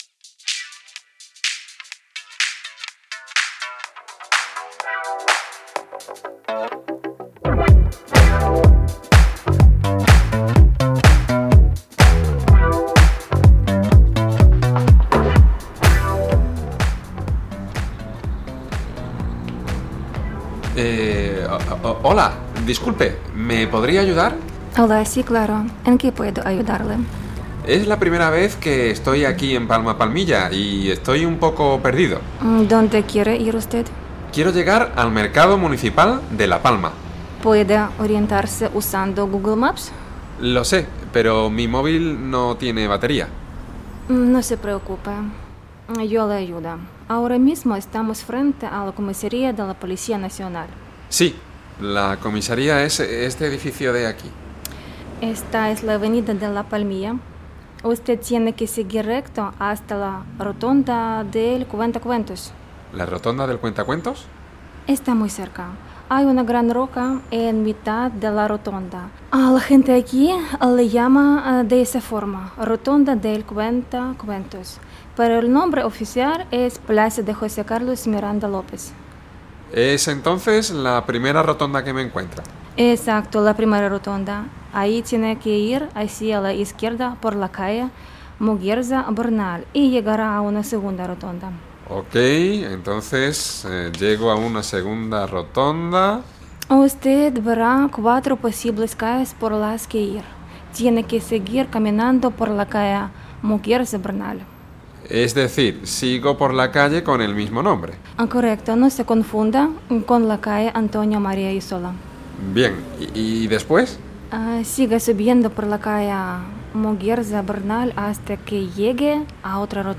Un hombre necesita llegar al mercado municipal de La Palma, pero está un poco lejos. Una vecina le da indicaciones.
• Este es un listening para aprender español, nivel básico. La historia dura 2,5 minutos; el resto son preguntas y otros datos.